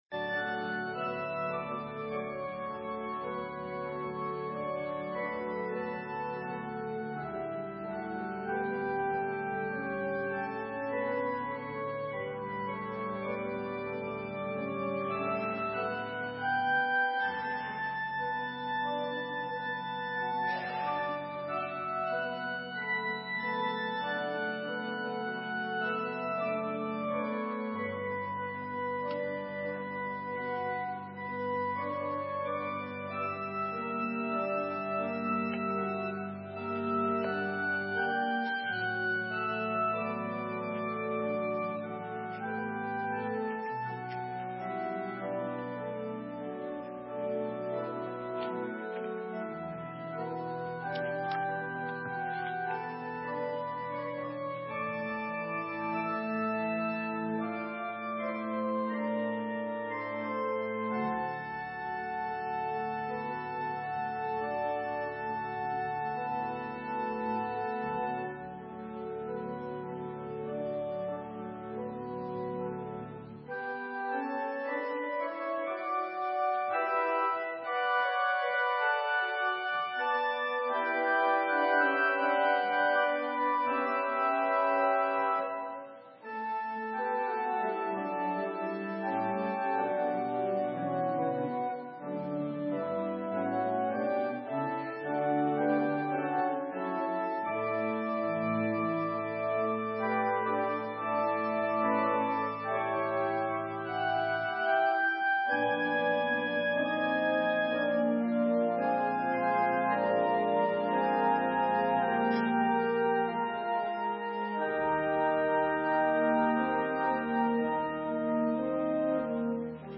Sermon:Everything you need - St. Matthews United Methodist Church